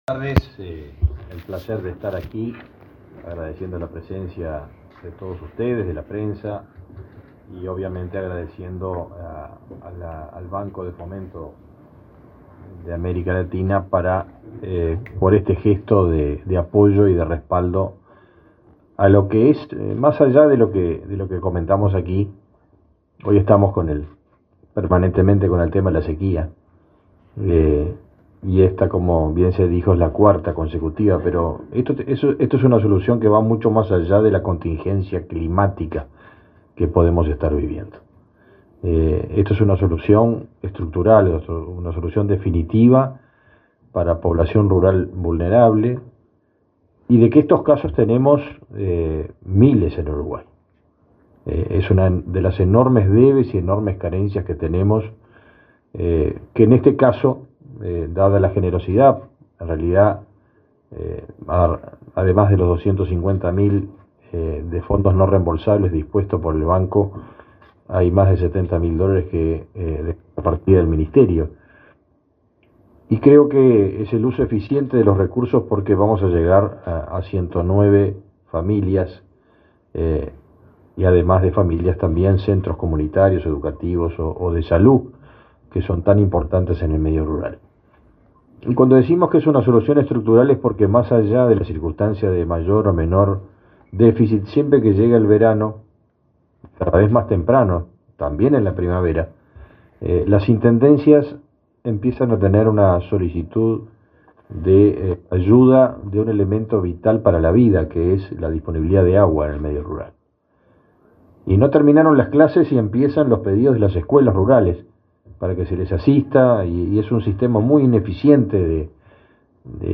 Palabras del ministro de Ganadería, Fernando Mattos